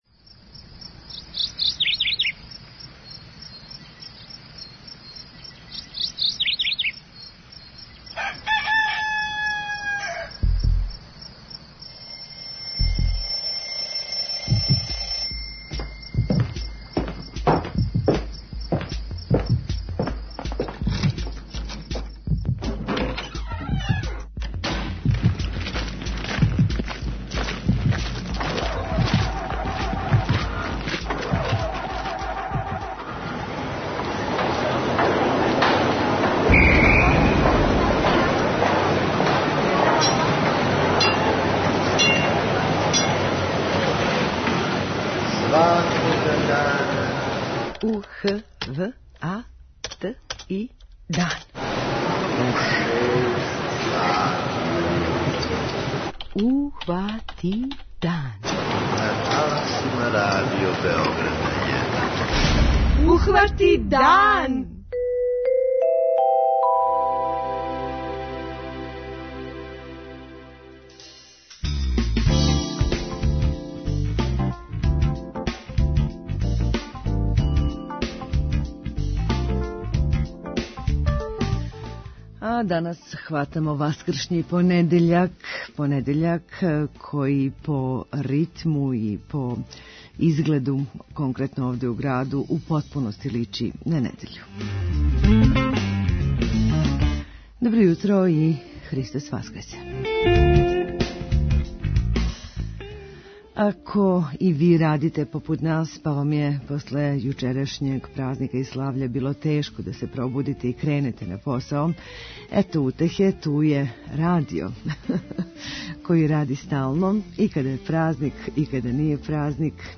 преузми : 32.33 MB Ухвати дан Autor: Група аутора Јутарњи програм Радио Београда 1!